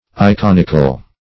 Search Result for " iconical" : The Collaborative International Dictionary of English v.0.48: Iconical \I*con"ic*al\, a. Pertaining to, or consisting of, images, pictures, or representations of any kind.